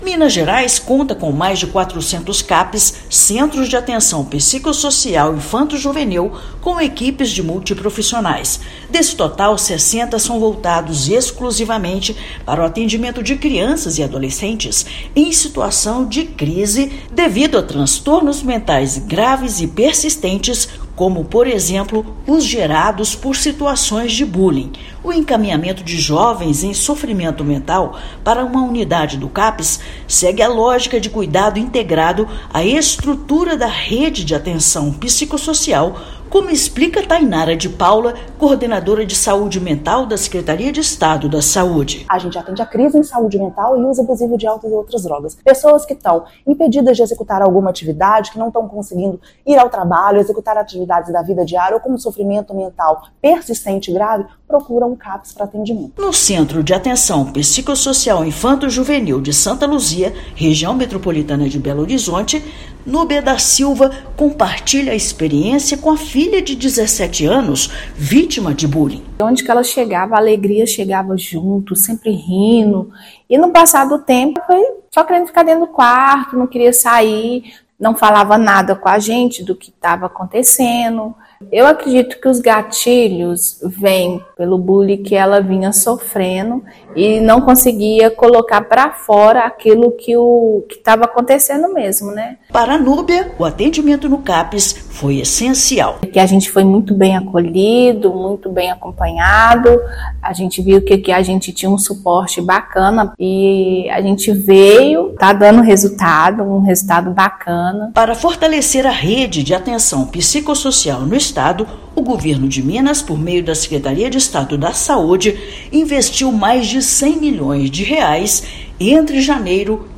Familiares e profissionais do Governo de Minas alertam para os sinais de sofrimento mental em crianças e adolescentes e reforçam a importância do acompanhamento. Ouça matéria de rádio.